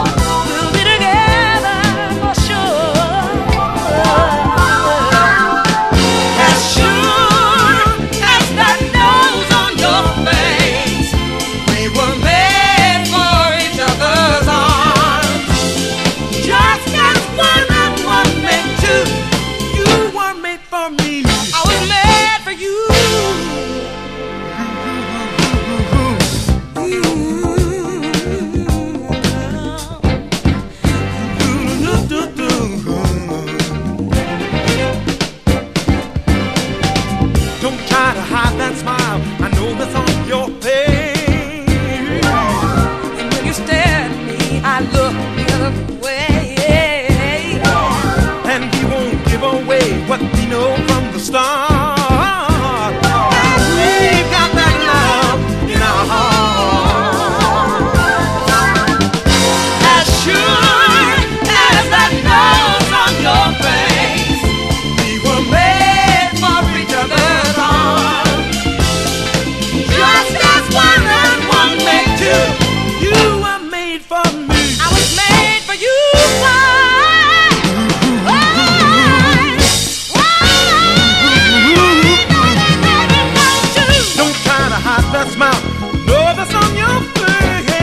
SOUL / SOUL / 70'S～ / FREE SOUL
温かい歌声に優しく包まれるメロウ・ダンサー